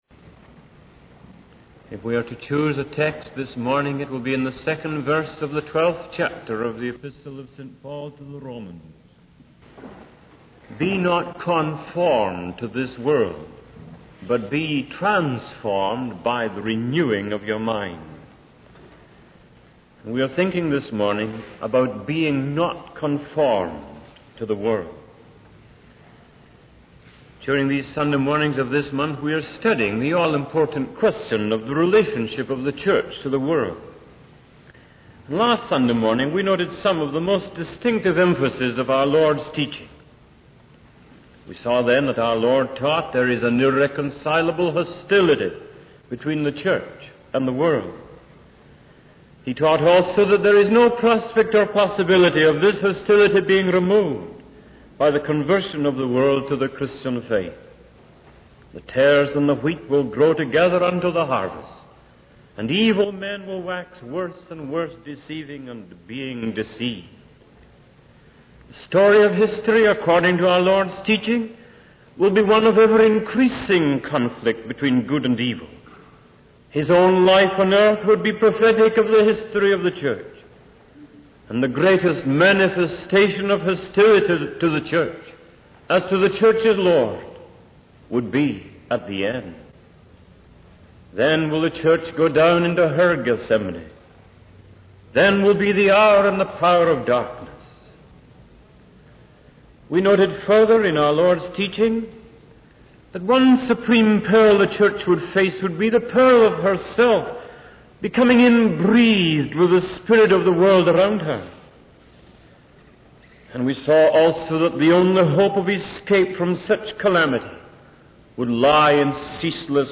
In this sermon, the preacher discusses the conflict between the Church and the world. He emphasizes that the Church is called to be different from the world and not conform to its ways.